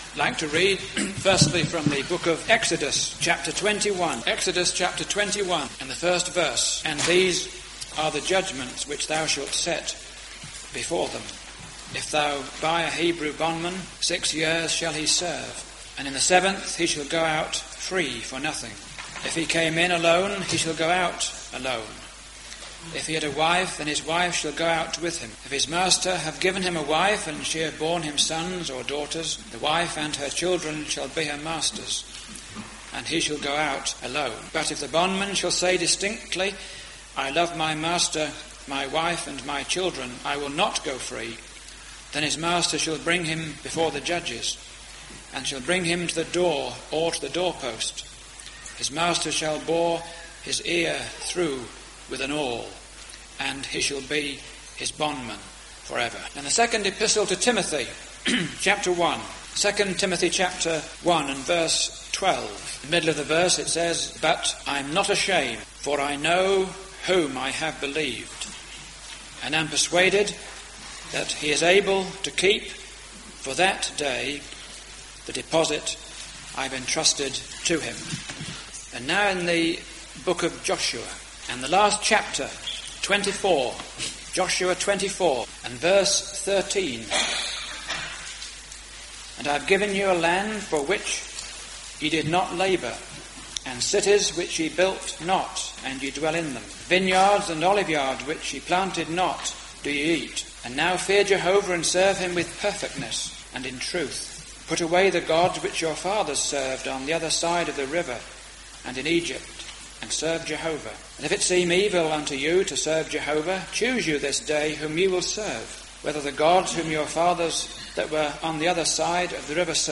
In this Address, you will hear a word as to the importance of being committed to the Lord Jesus.